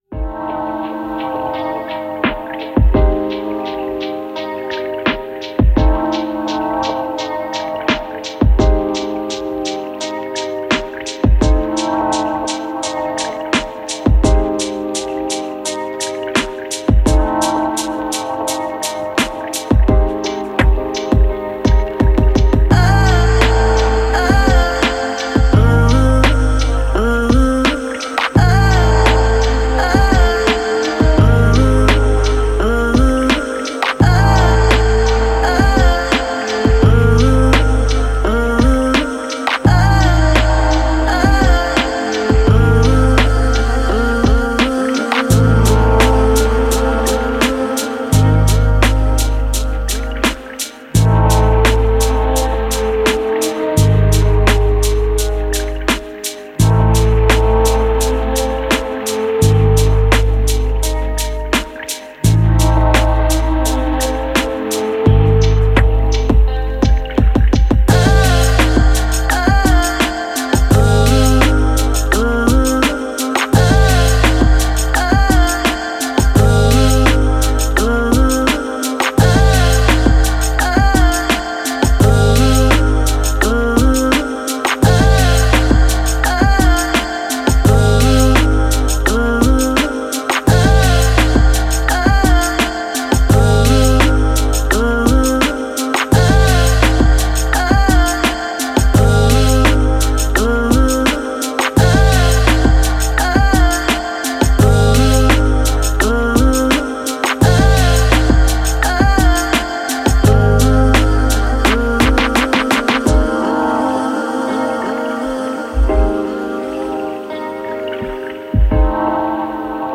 Musique chill libre de droit pour vos projets.